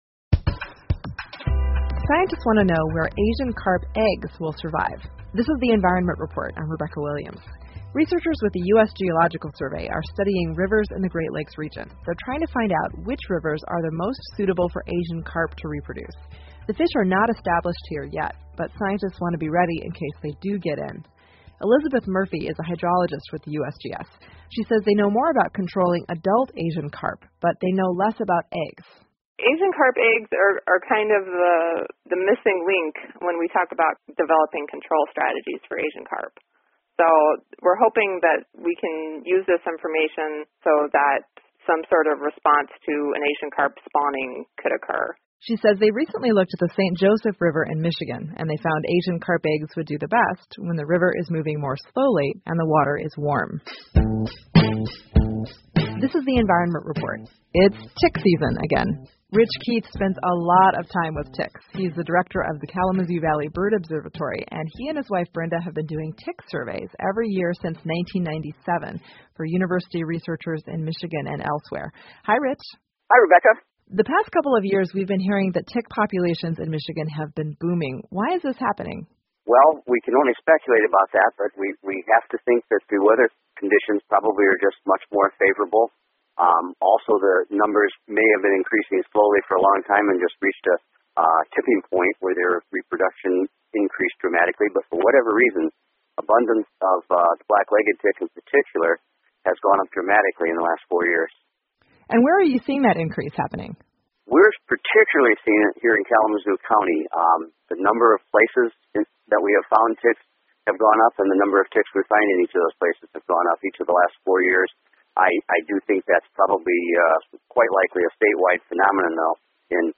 密歇根新闻广播 密歇根蜱虫繁盛期到来,谨防莱姆病 听力文件下载—在线英语听力室